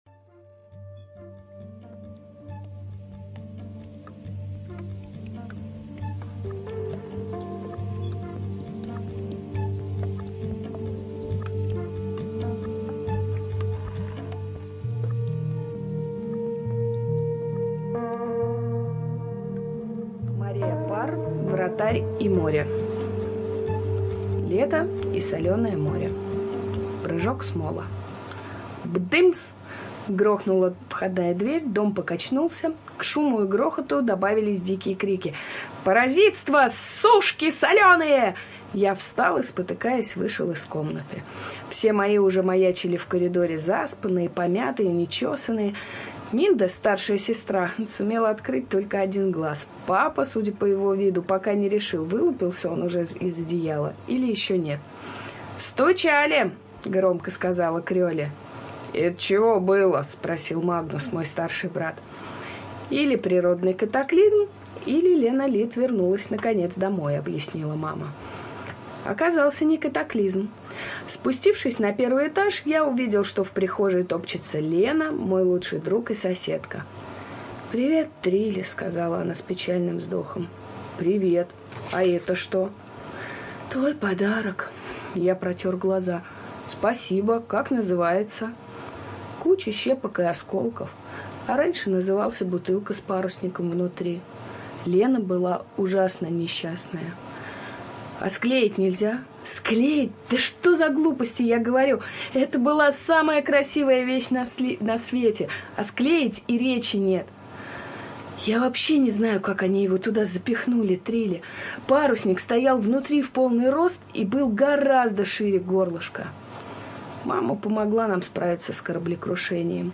ЖанрДетская литература